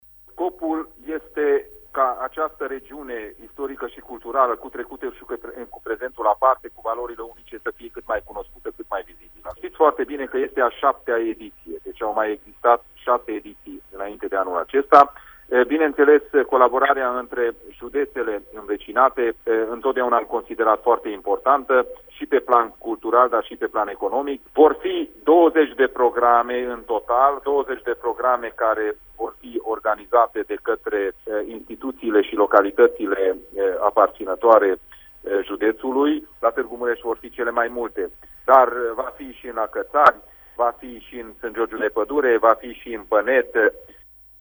Președintele Consiliului Județean Mureș, Peter Ferencz: